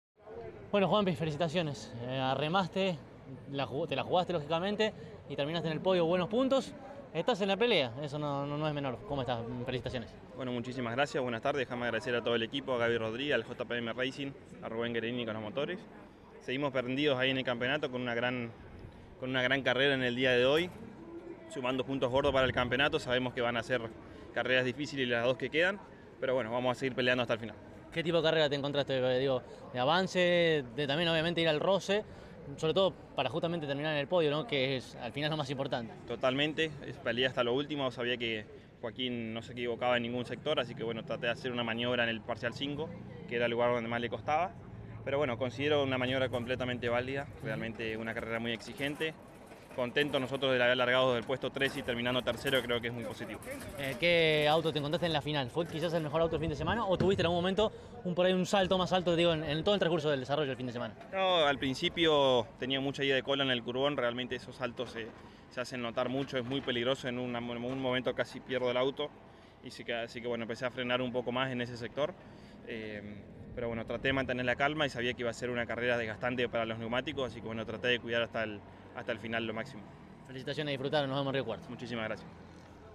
Este fin de semana, el Turismo Nacional disputó su décima fecha de la temporada y la primera de las dos finales en disputarse del domingo fue la de la Clase 2. Tras ella, los integrantes del podio, dialogaron con CÓRDOBA COMPETICIÓN.